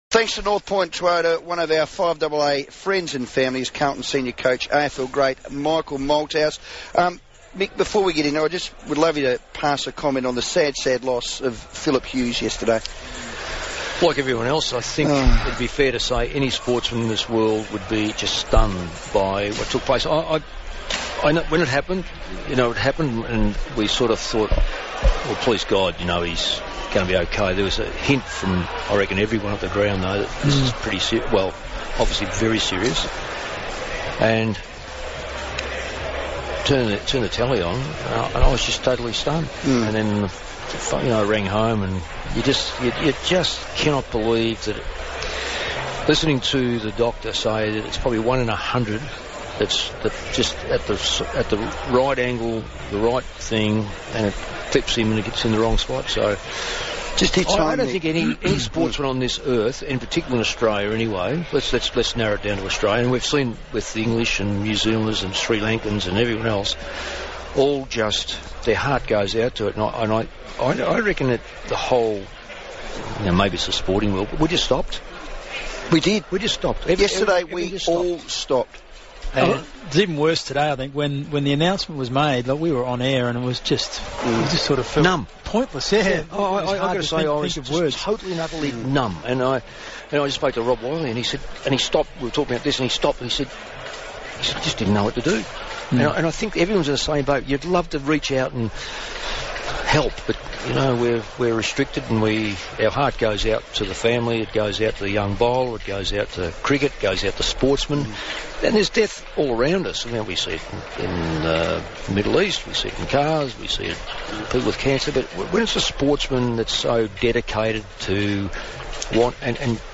Coach Mick Malthouse chatted to Adelaide's FIVEaa on Thursday, November 27.